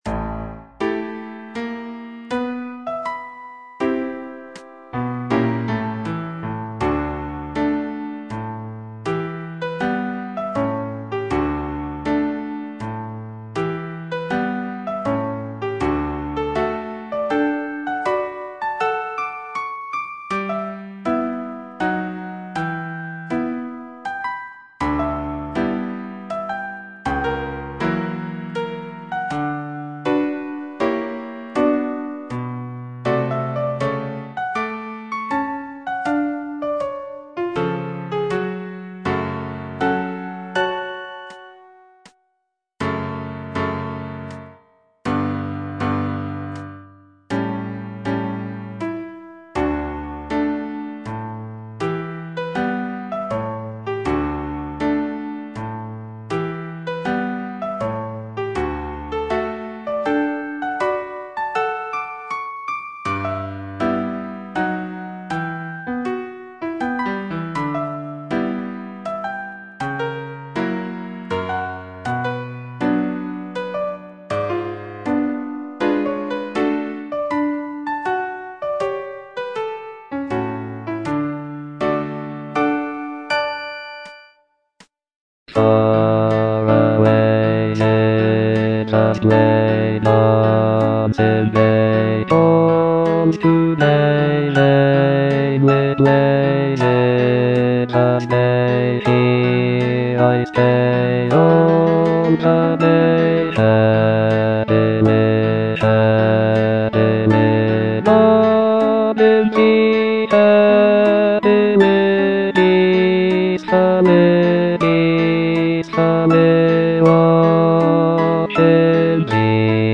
bass I) (Voice with metronome